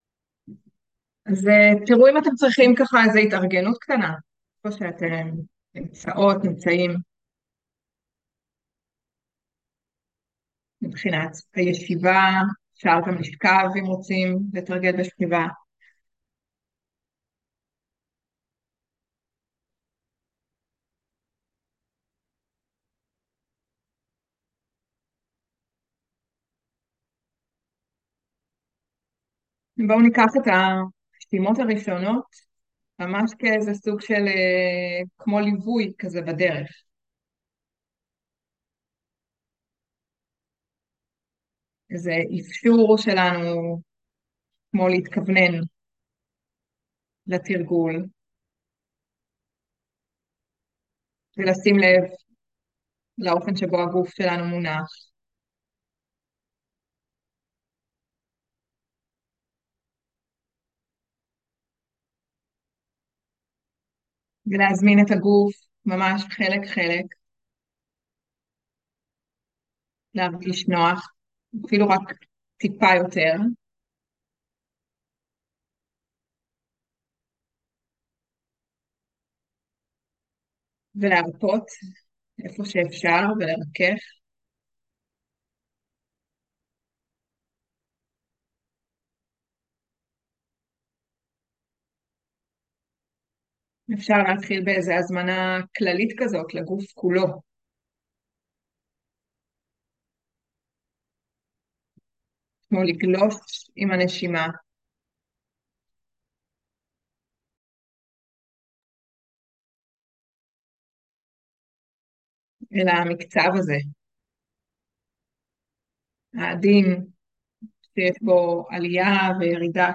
23.10.2023 - מרחב בטוח - לעגון בחוף המבטחים של עצמנו - מדיטציה ומטא מונחית